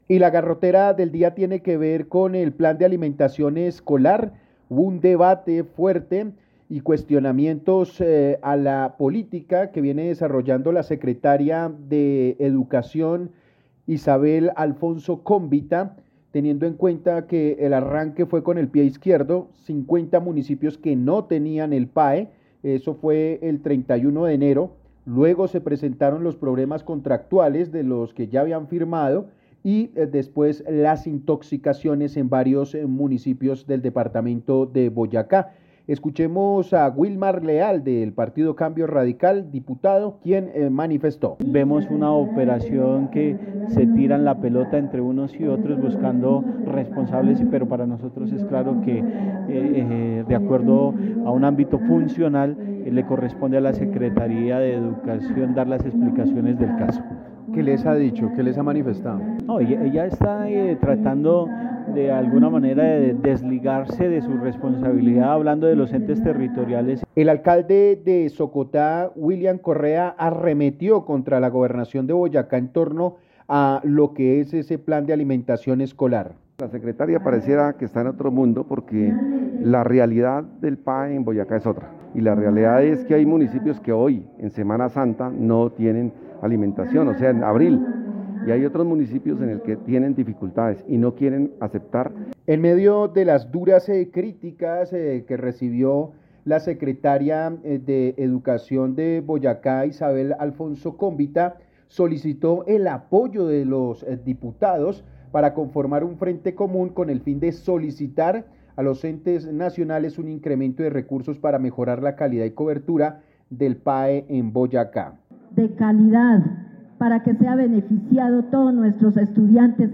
Durante un debate de control político, el diputado de Cambio Radical, Wilmar Leal, pidió a la secretaria de Educación de Boyacá, Isabel Alfonso Cómbita, más responsabilidad frente a la ejecución del Plan de Alimentación Escolar (PAE) que ha tenido cientos de niños intoxicados, contratación tardía y municipios que aún no comienzan con el programa.